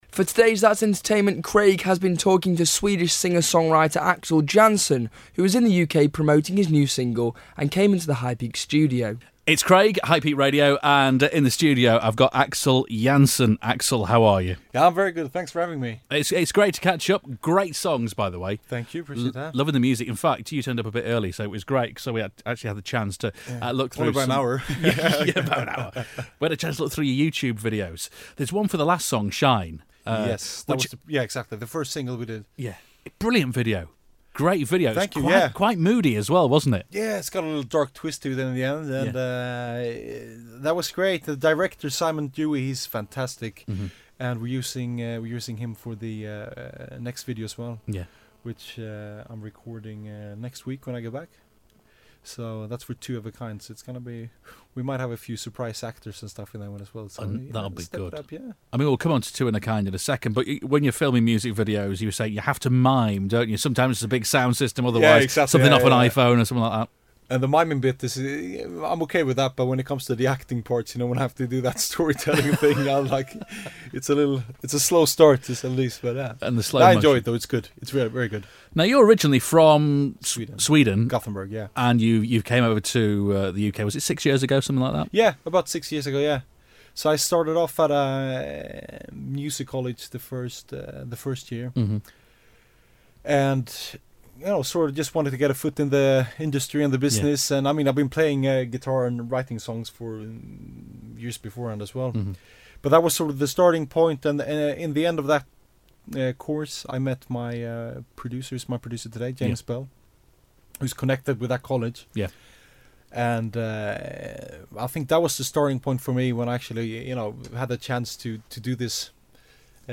Swedish singer-songwriter
an acoustic version